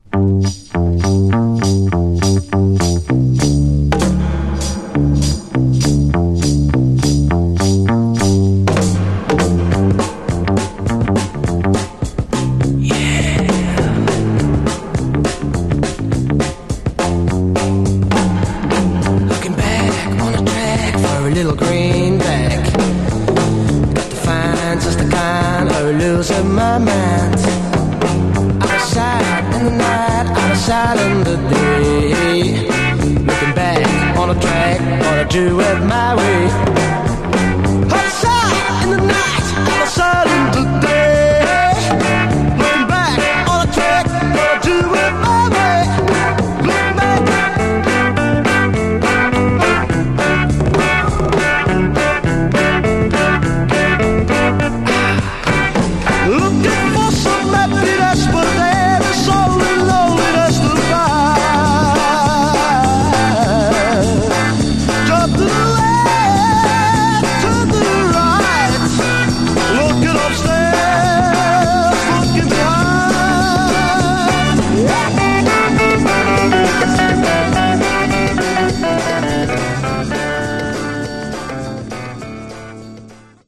Genre: Power Pop